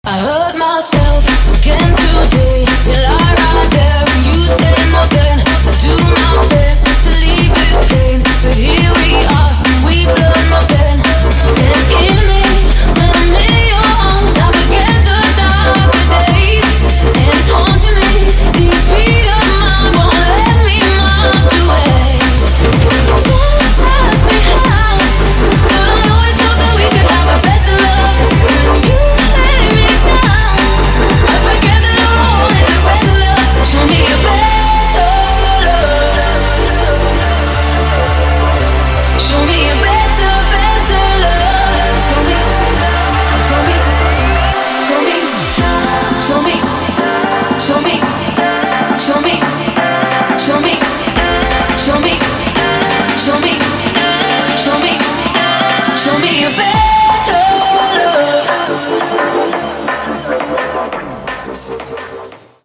• Качество: 128, Stereo
drum&bass